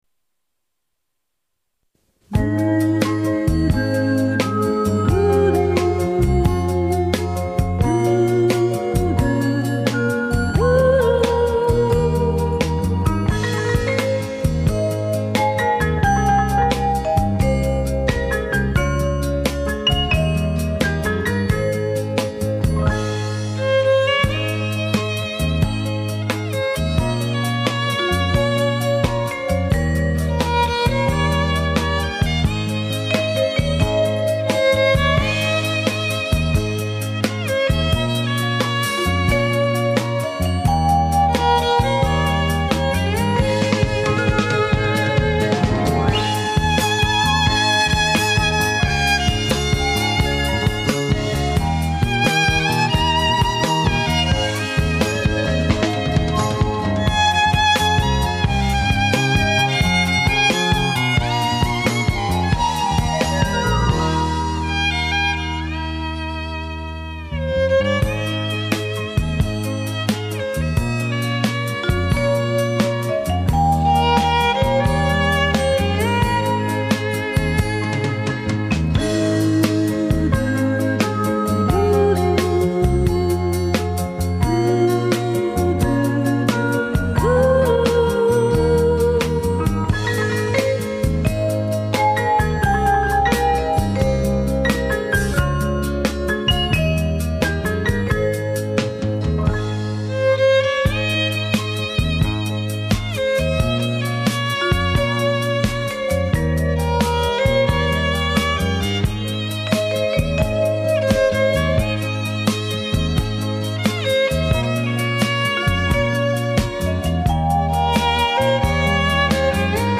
吉他